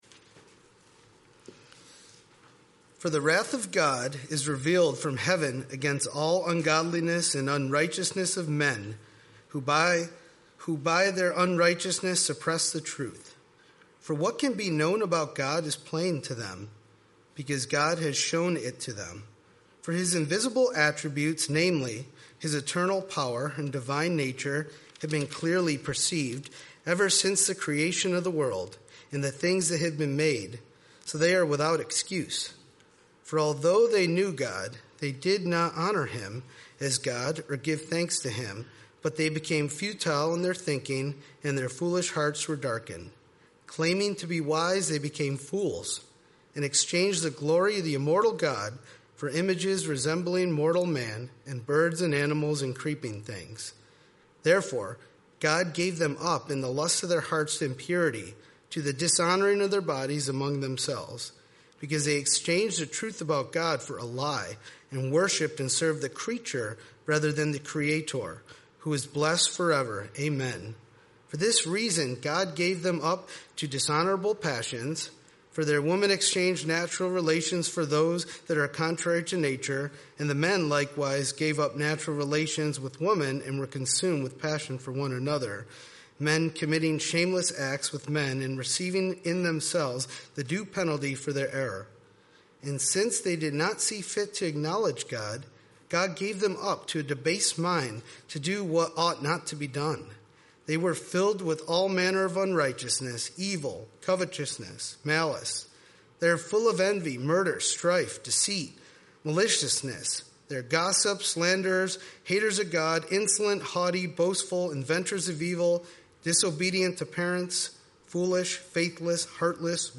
Sermons | Evangelical Church of Fairport